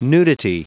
Prononciation du mot nudity en anglais (fichier audio)
nudity.wav